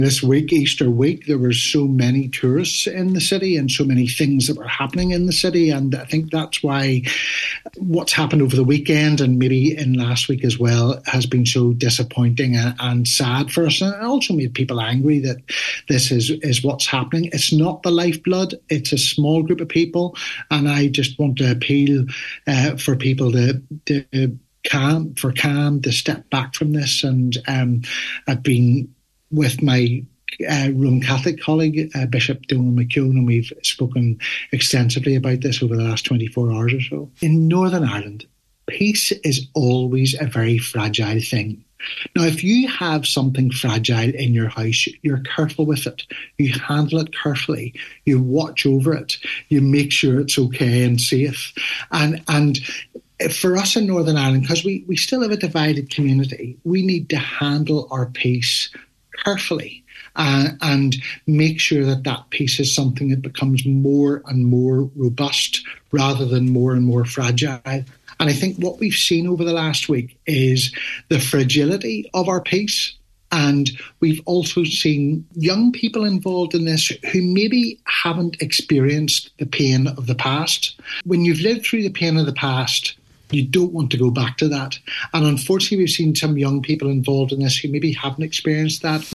On today’s Nine til Noon Show, Church of Ireland Bishop Andrew Forster called for calm, saying these incidents do not represent the lifeblood of the city.